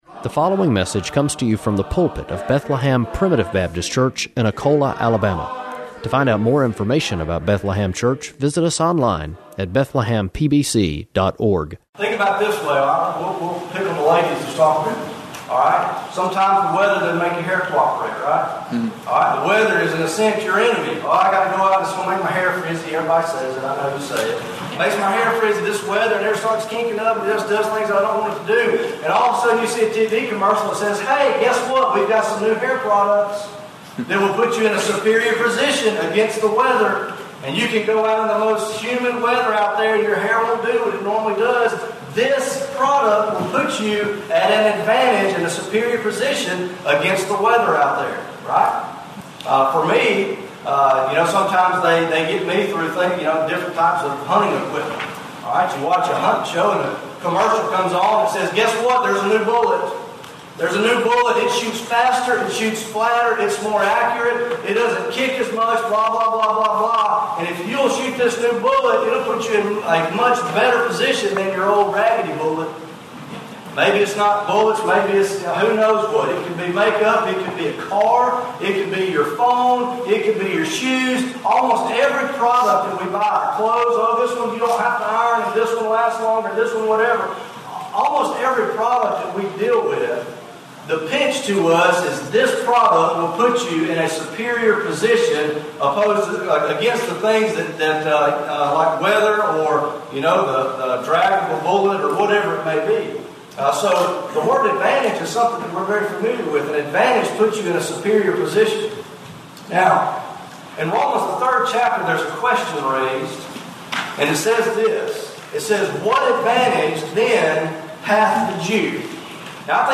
Preached June 18